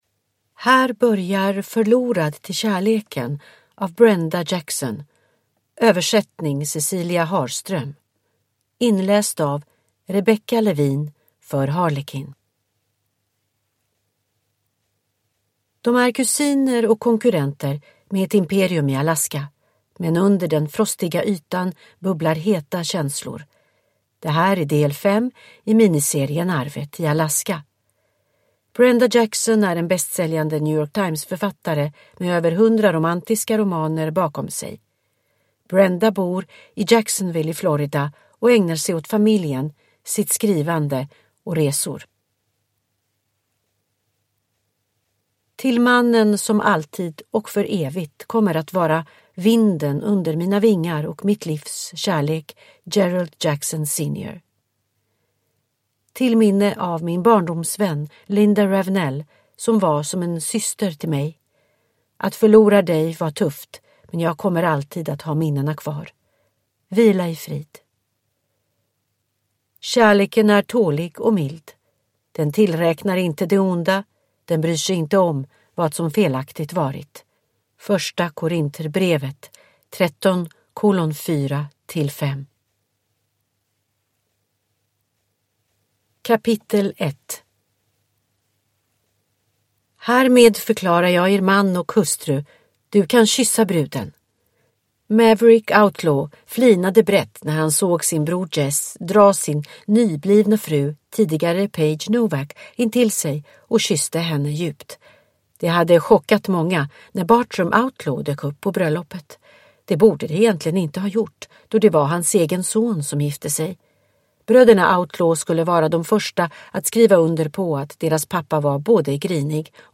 Förlorad till kärleken (ljudbok) av Brenda Jackson